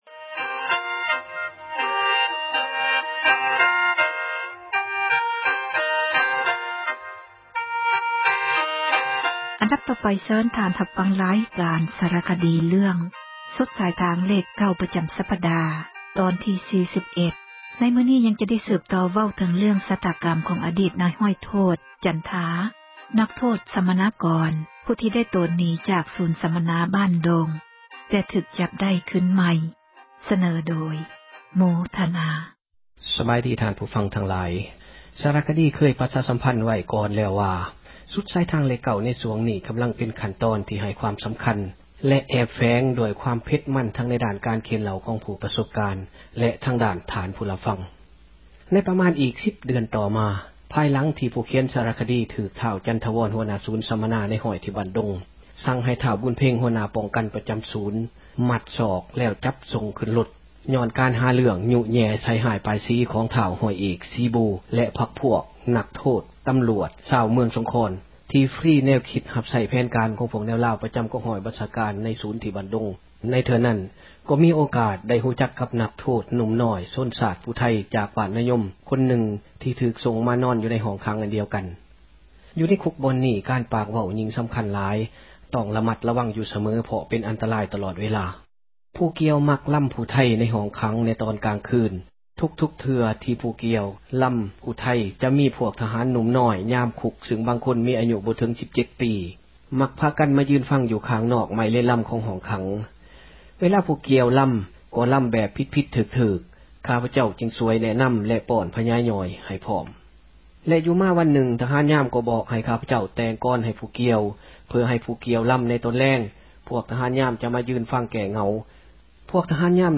ຣາຍການ ສາຣະຄະດີ